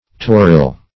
Search Result for " torril" : The Collaborative International Dictionary of English v.0.48: Torril \Tor"ril\, n. A worthless woman; also, a worthless horse.